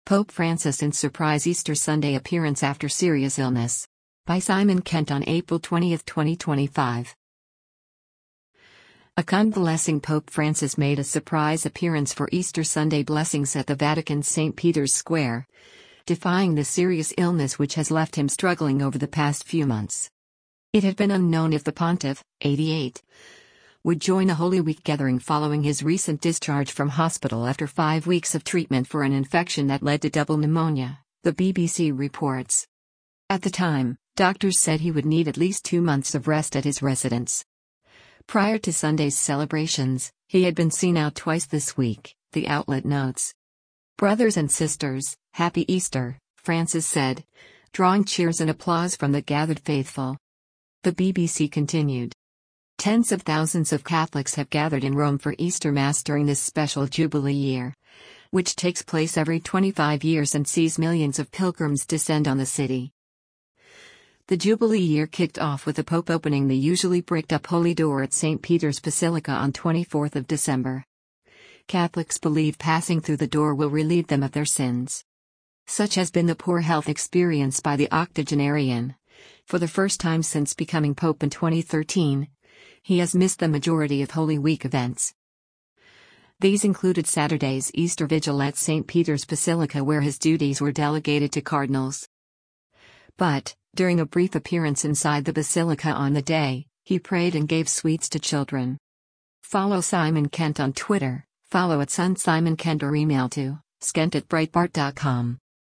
Pope Francis waves from the main balcony of St. Peter's basilica for the Urbi et Orbi mess
“Brothers and sisters, Happy Easter!” Francis said, drawing cheers and applause from the gathered faithful.